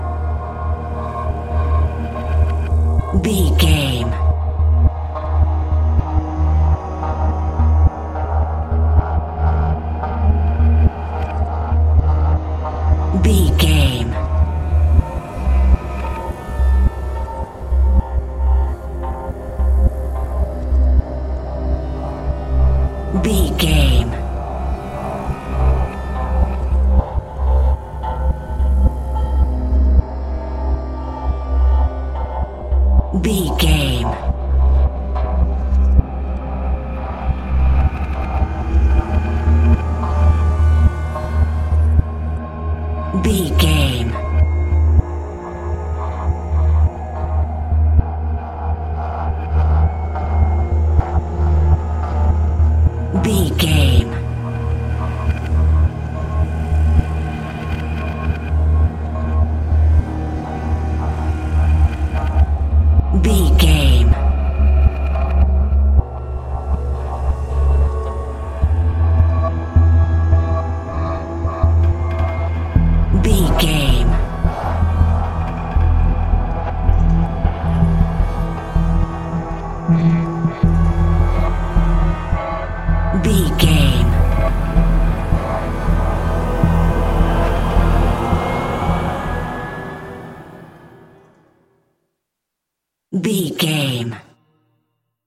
Thriller
Aeolian/Minor
E♭
Slow
synthesiser